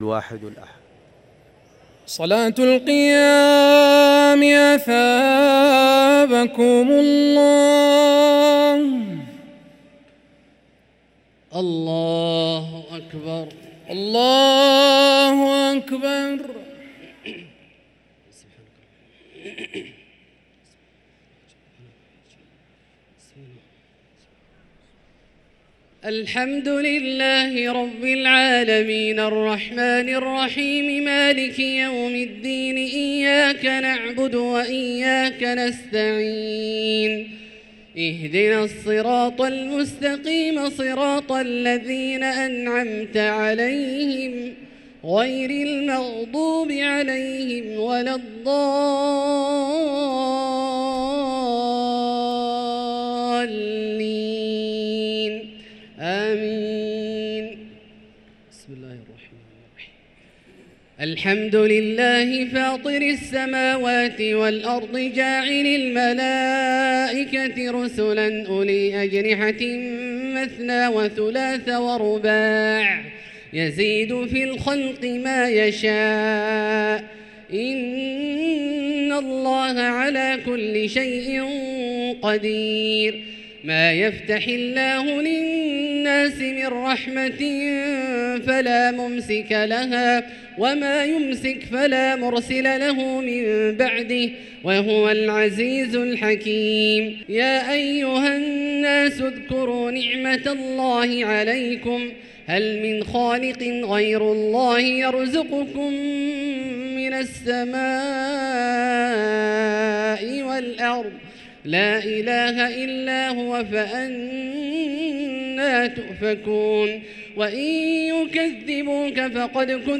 صلاة التراويح ليلة 25 رمضان 1444 للقارئ عبدالله الجهني - الثلاث التسليمات الأولى صلاة التراويح